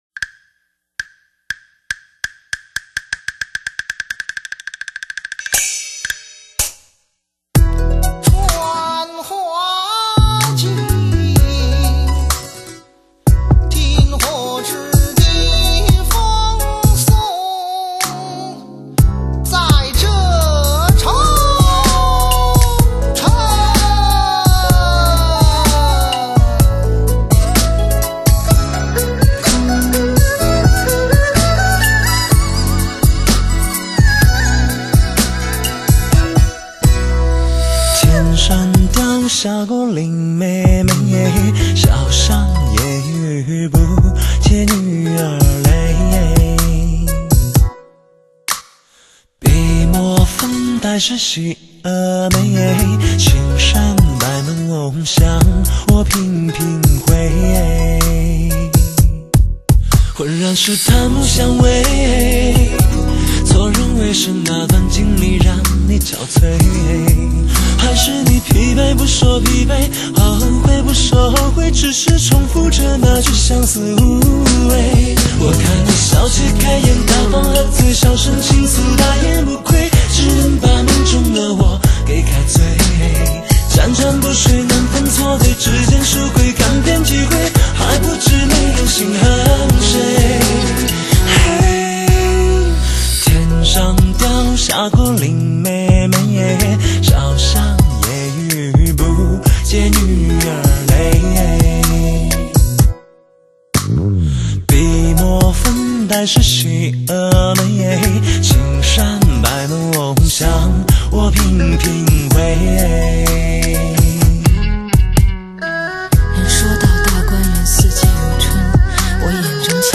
悲情故事歌曲的演绎也从一昧的凄惨跌宕的演唱方法发展出了举重若轻的表现形式。